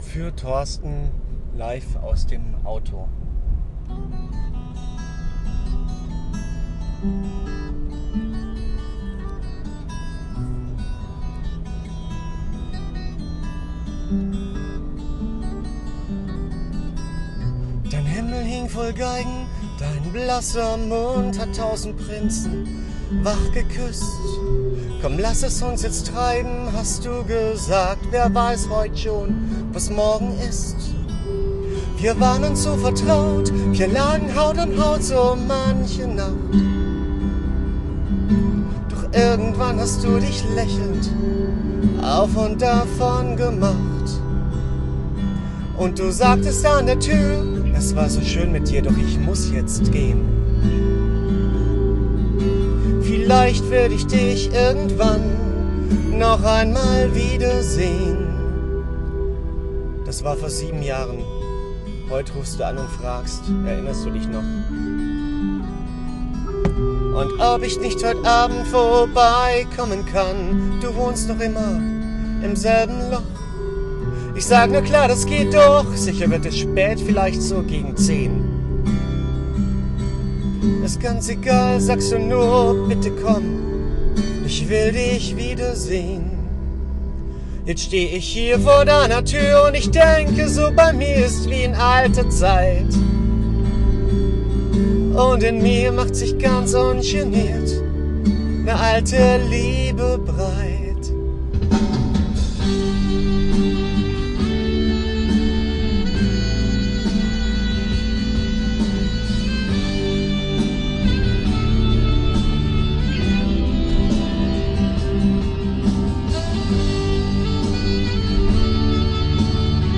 Es scheppert, klirrt und kracht - wie mein Herz.